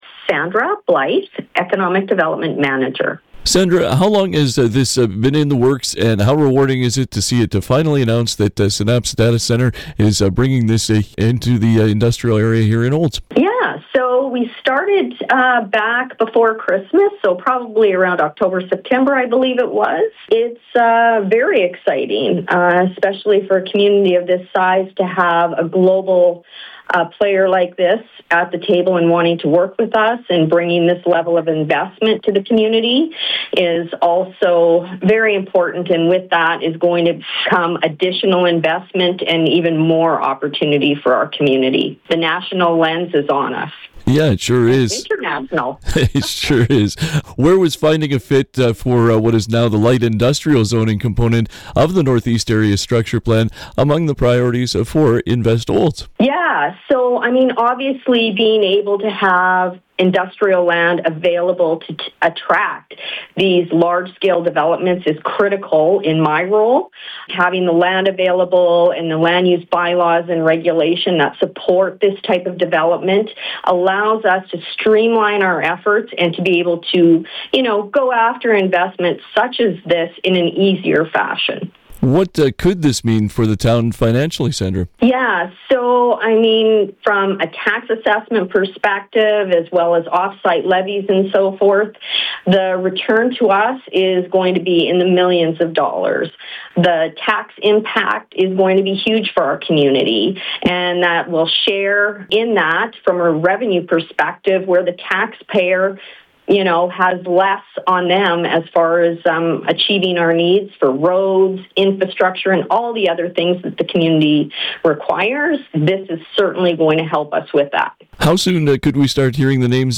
Community Hotline conversation